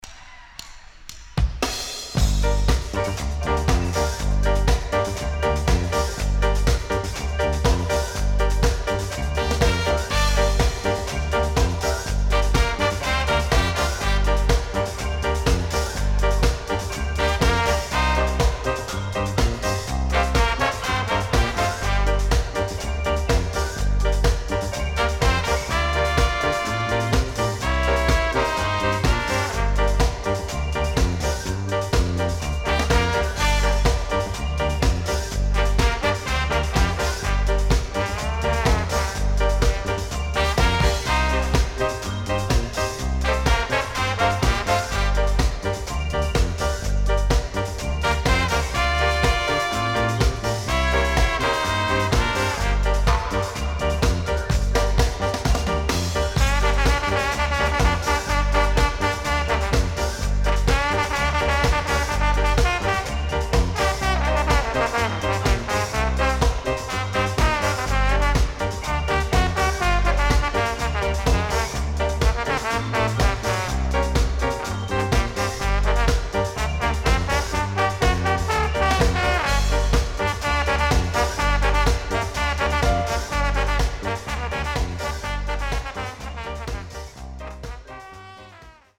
正式メンバーにミキサーが在籍するライヴDUBバンドとして数々の伝説を作り、89年に解散。